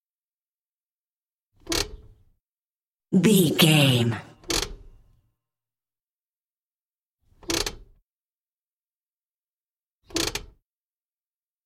Car handbrake
Sound Effects
transportation